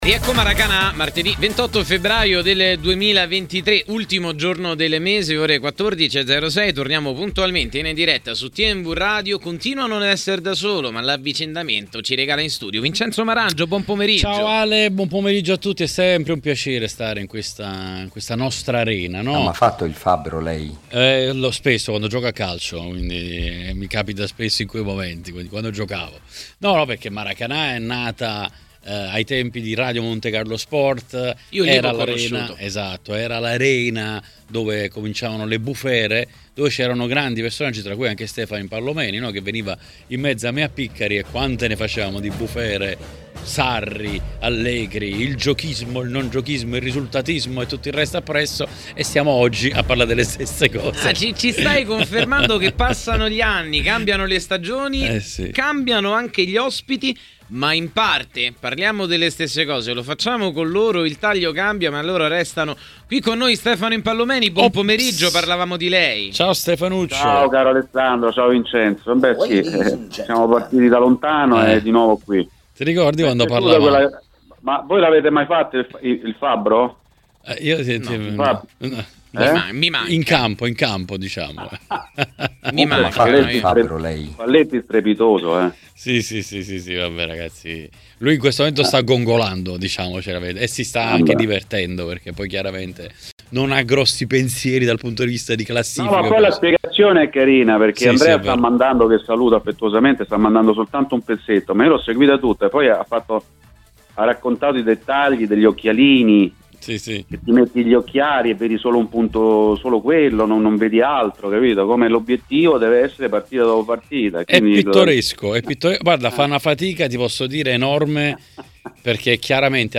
A commentare le notizie di giornata a Maracanà, nel pomeriggio di TMW Radio